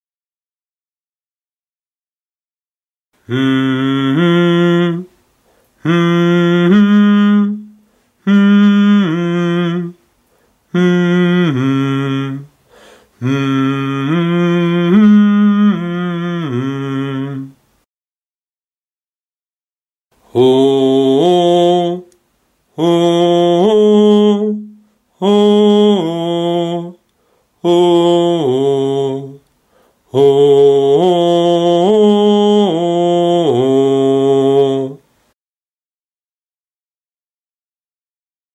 First I sing them humming, then with a “HOU” sound. These are real-life sounds with no autotuning or anything.